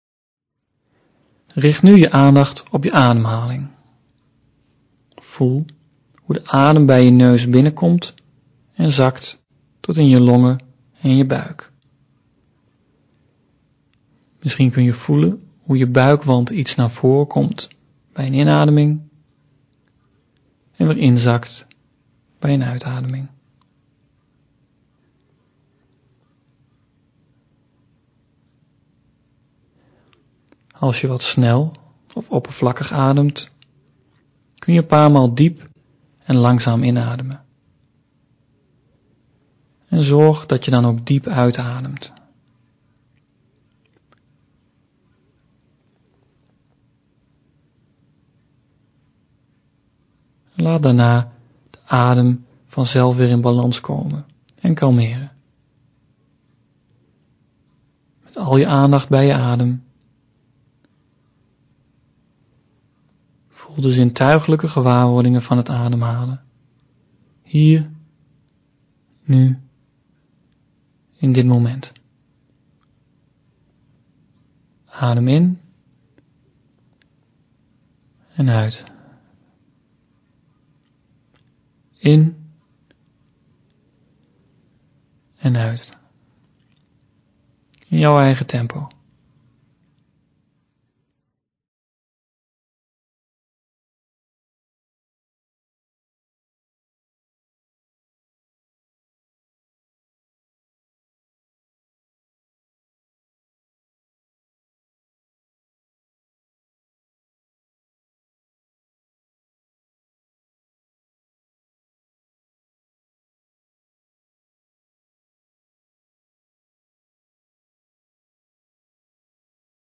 Zitmeditatie
Audio introductie